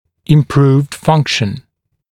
[ɪm’pruːvd ‘fʌŋkʃn][им’пру:вд ‘фанкшн]улучшенная функция; улучшение функциональных показателей